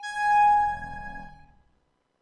描述：通过Behringer调音台采样到MPC 1000。它听起来一点也不像风的部分，因此被称为Broken Wind。
标签： 模拟 多样品 合成器 虚拟模拟
声道立体声